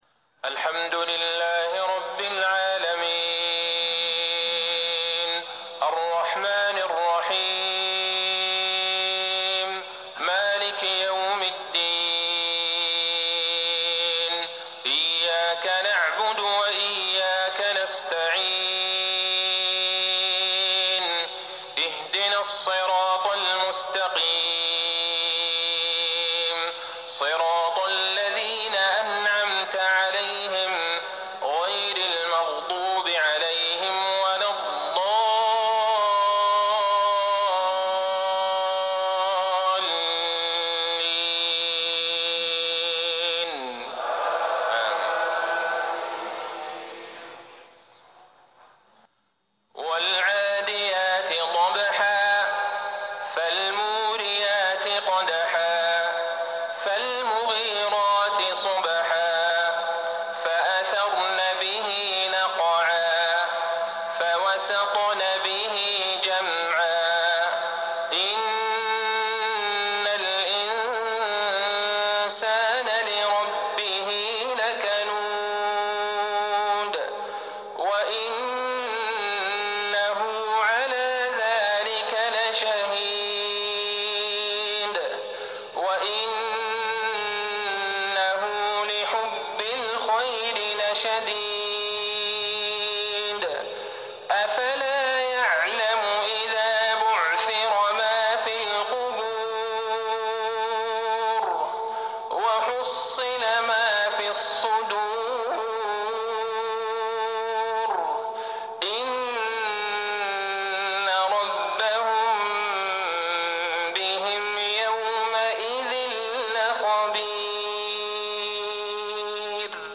صلاة المغرب 1-6-1440هـ سورتي العاديات والتكاثر | maghrib prayer from Surah Al-Adiyat & At-Takathur| 6-2-2019 > 1440 🕌 > الفروض - تلاوات الحرمين